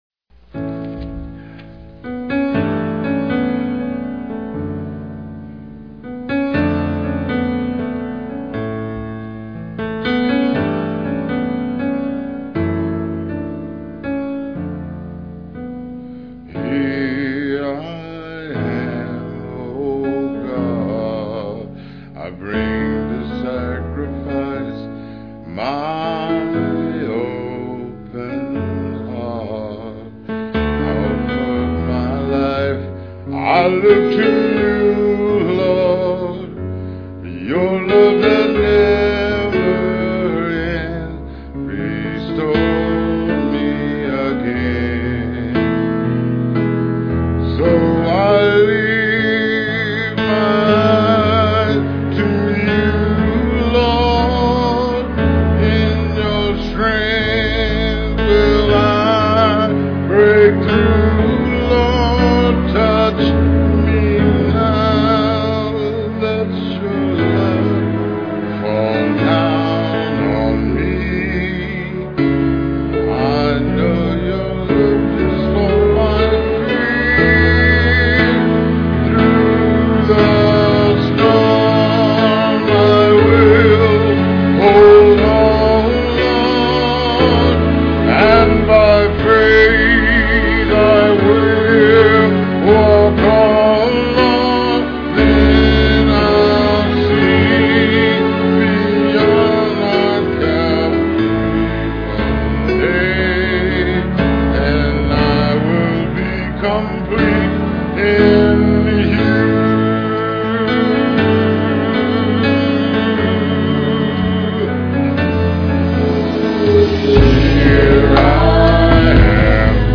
ABC choir.